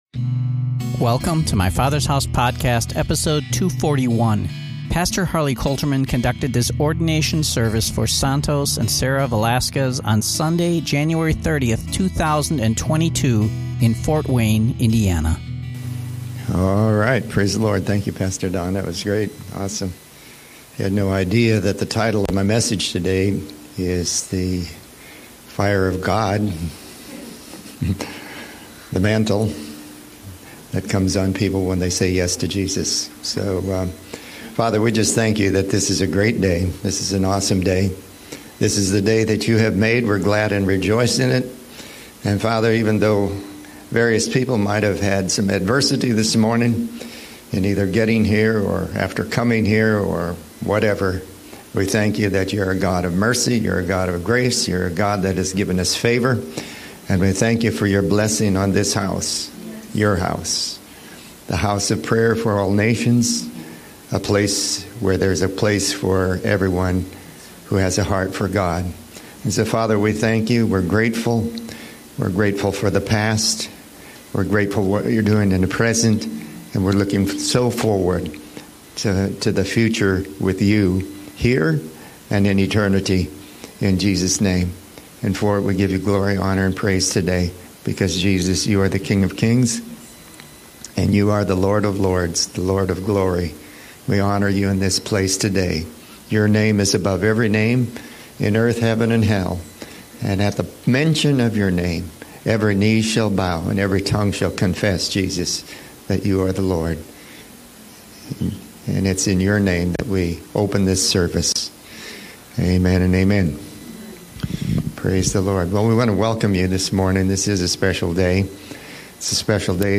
Ordination Service